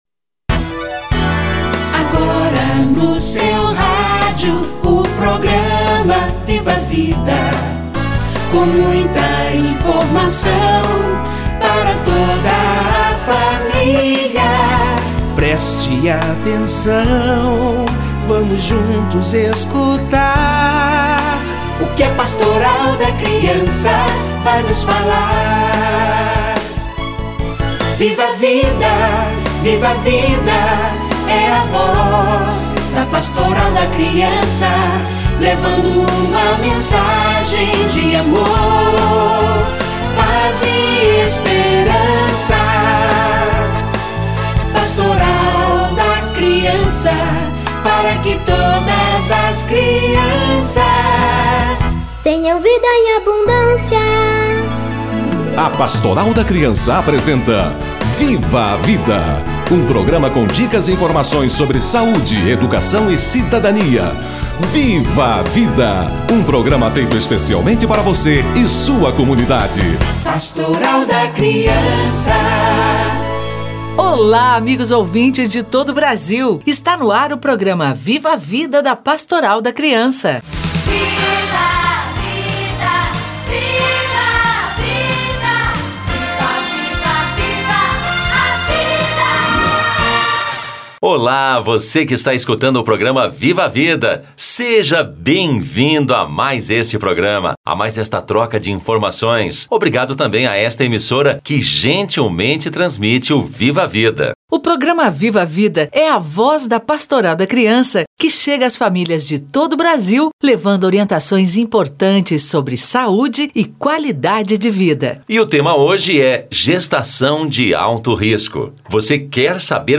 Cuidados gestantes - Entrevista